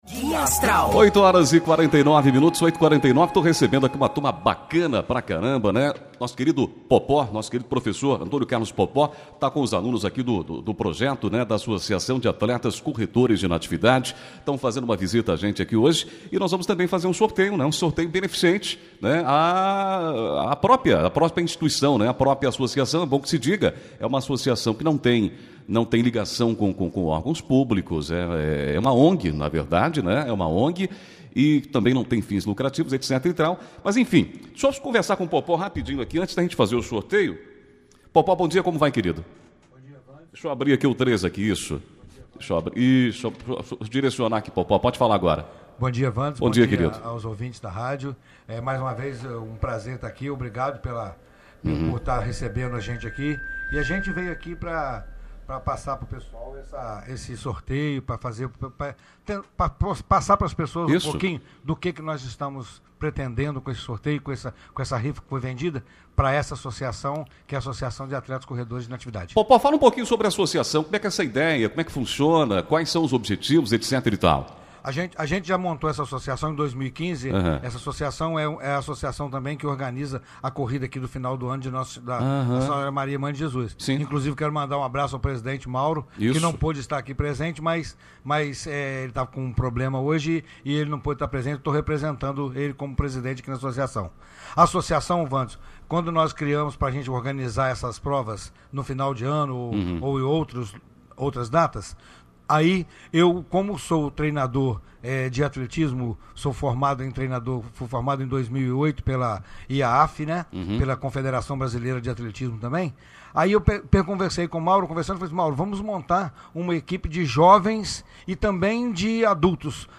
16 julho, 2018 ENTREVISTAS, FaceLIVE, NATIVIDADE AGORA
ENTREVISTA-ATLETAS.mp3